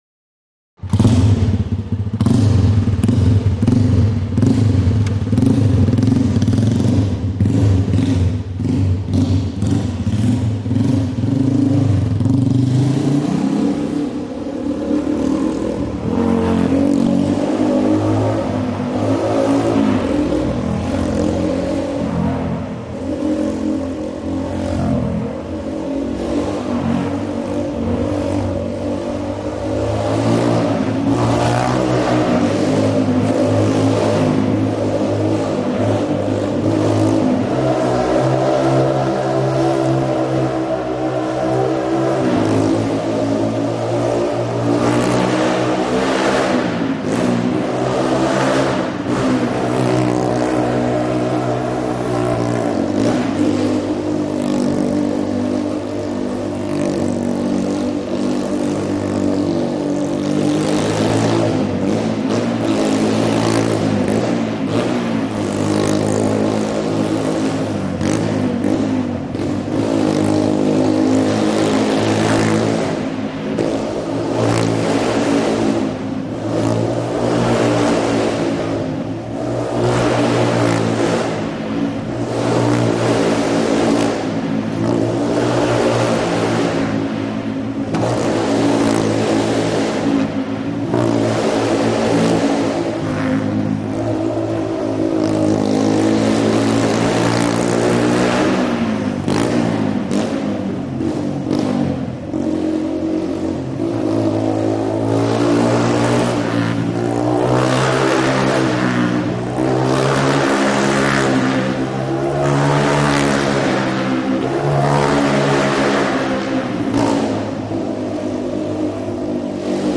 250 Dirt Bike
Motorcycle; Start / Circles; Motorbike Start Up, Then Goes Round Wall Of Death, Finally Slows, Stops And Switch Off.